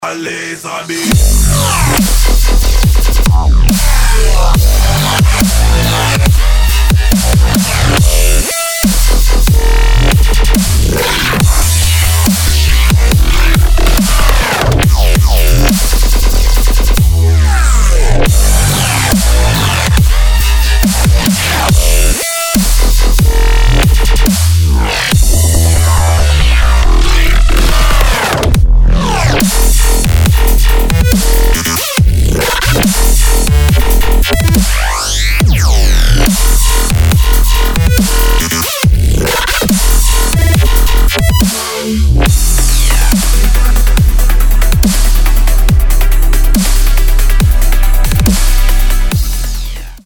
• Качество: 192, Stereo
жесть
Дабстеп версия недавно вышедшего хита